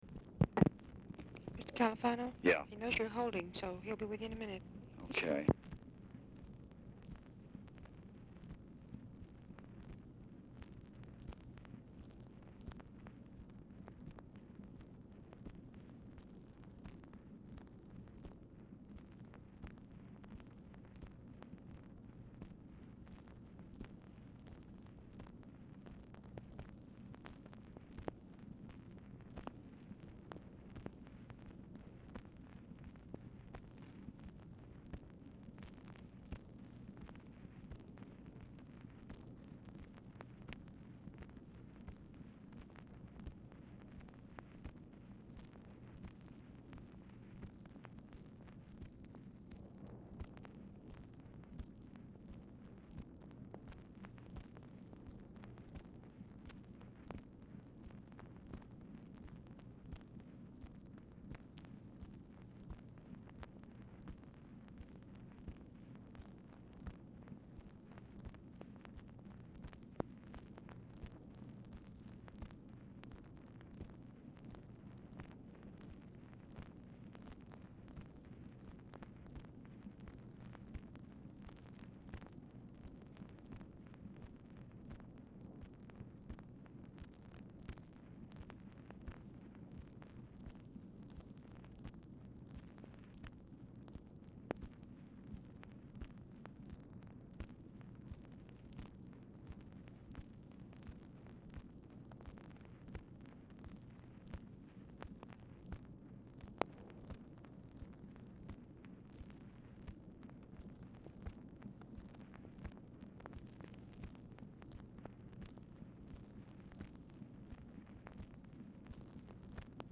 Telephone conversation # 10455, sound recording, LBJ and JOSEPH CALIFANO, 7/29/1966, 9:15PM | Discover LBJ
Format Dictation belt
Specific Item Type Telephone conversation